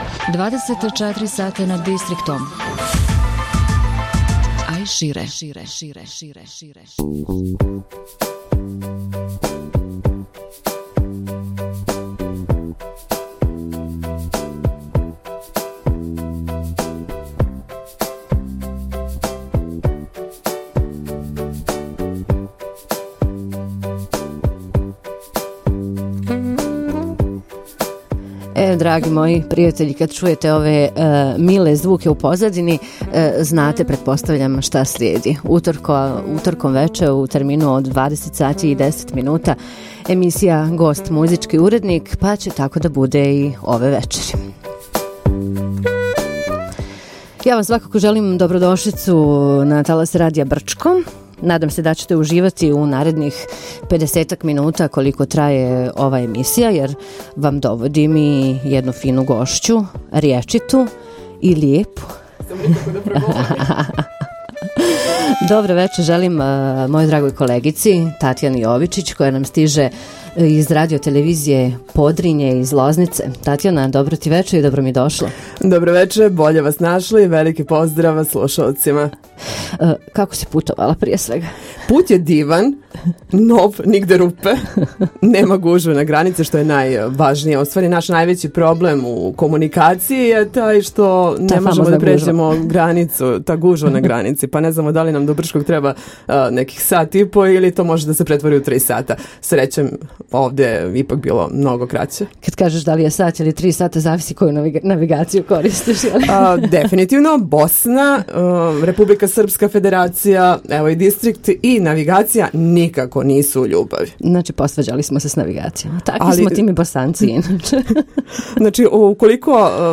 Emisija "Gost - muzički urednik" - Razgovor